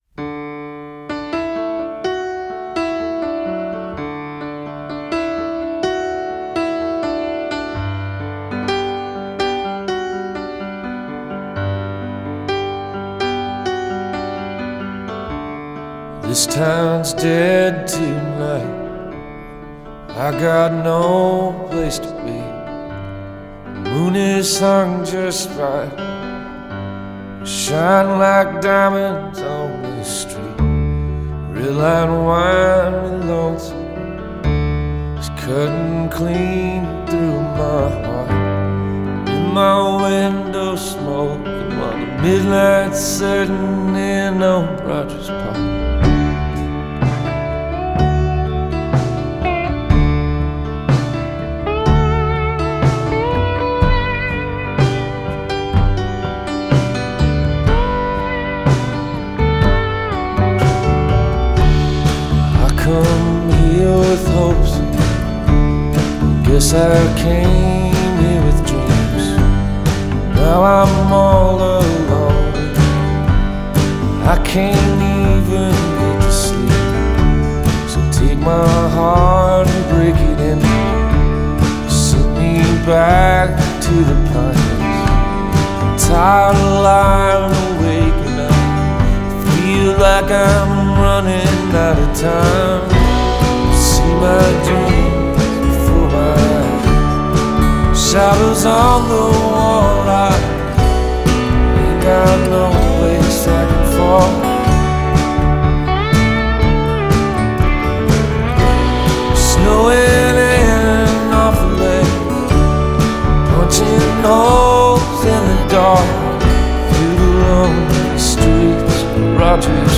rousing heart-wrenching ballad